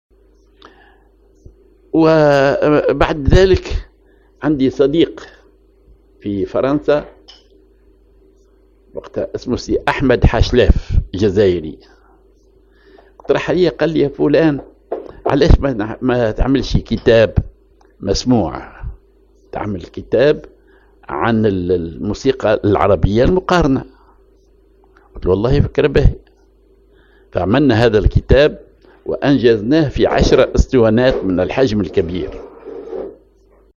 en The audiobook includes ten 33 RPM records containing 37 lectures translated into French, covering the most important characteristics of music in various Arab and Islamic countries. The lectures are accompanied by musical examples from each of these countries.